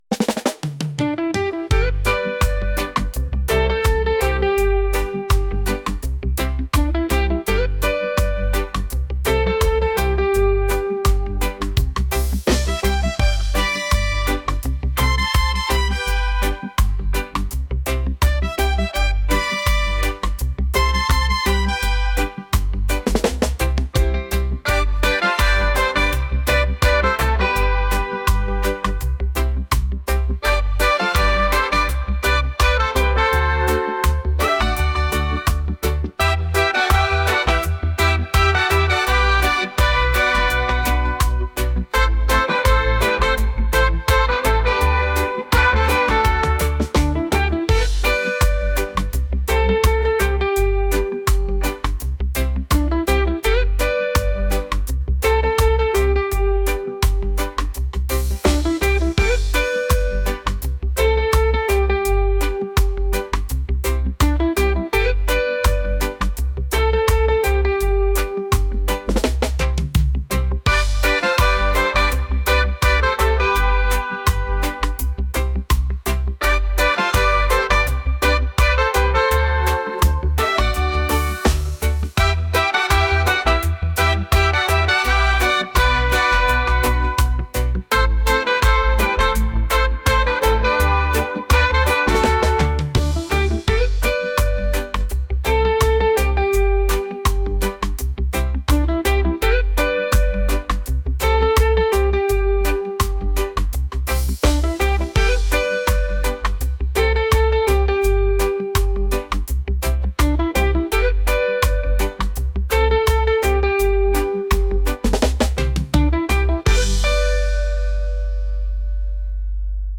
reggae | pop | soul & rnb